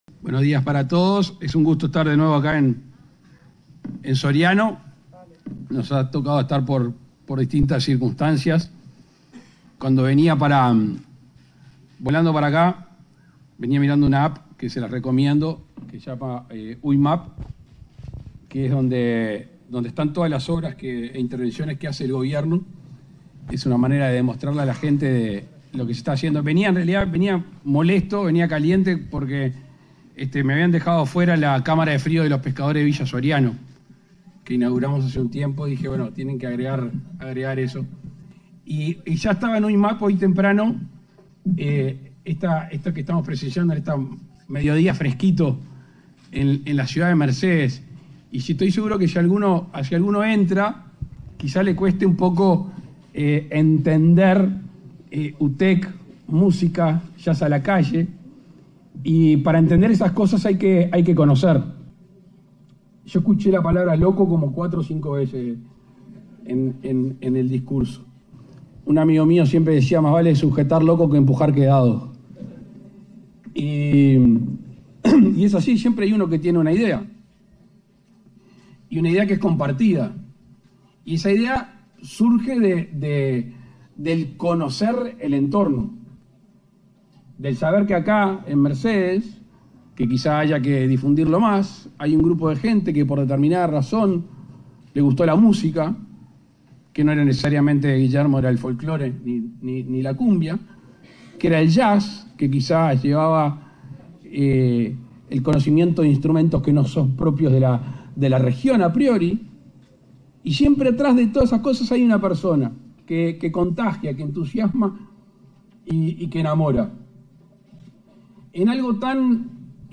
Palabras del presidente de la República, Luis Lacalle Pou
En el marco de la ceremonia de inauguración de las obras de ampliación de la sede de la Universidad Tecnológica del Uruguay (UTEC), este 5 de junio,